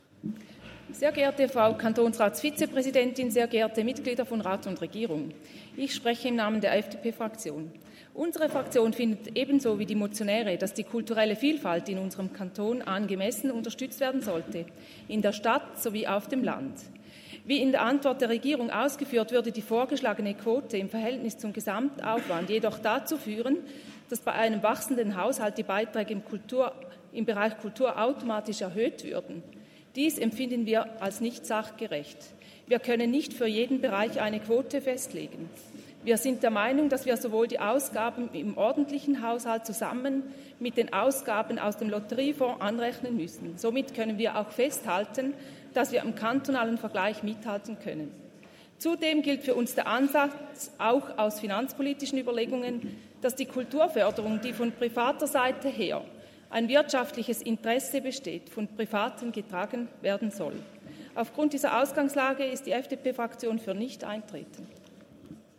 Session des Kantonsrates vom 29. April bis 2. Mai 2024, Aufräumsession
1.5.2024Wortmeldung
Abderhalden-Nesslau (im Namen der FDP-Fraktion): Auf die Motion ist nicht einzutreten.